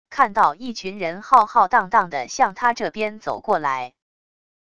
看到一群人浩浩荡荡的向他这边走过来wav音频生成系统WAV Audio Player